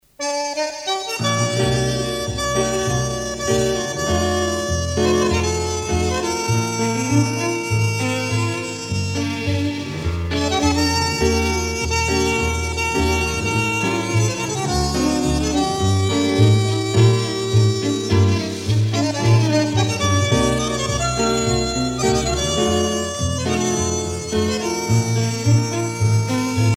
danse : tango
Pièce musicale éditée